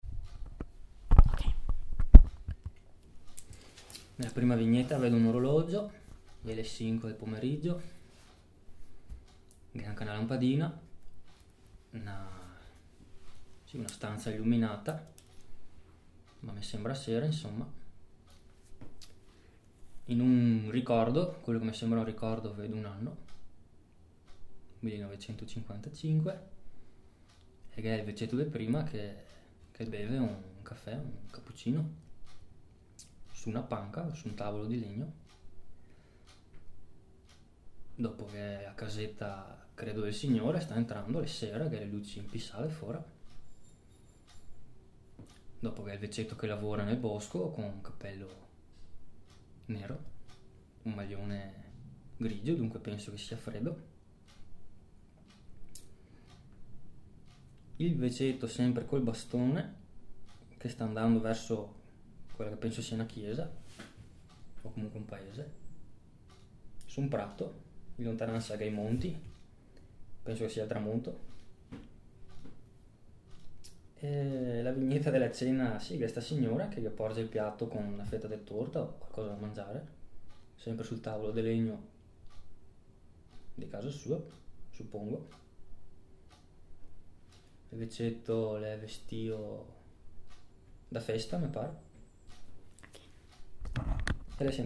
Lo stimolo Pa04_23m:
Dopo le domande a livello sociolinguistico, ai 5 parlanti è stato chiesto di raccontare una storia in base ad un fumetto creato dalla ricercatrice (fumetto). La scelta delle immagini è stata funzionale: esse hanno potuto garantire una produzione spontanea di stimoli fonici da parte del parlante, riportando frammenti autentici di vita parlata (un vantaggio rispetto agli stimoli basati sulla semplice lettura di un testo).